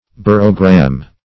Search Result for " barogram" : The Collaborative International Dictionary of English v.0.48: Barogram \Bar"o*gram\, n. [Gr. ba`ros weight + -gram.]